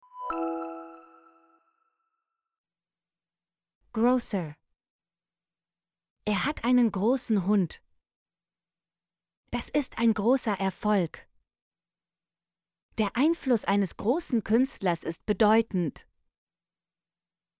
großer - Example Sentences & Pronunciation, German Frequency List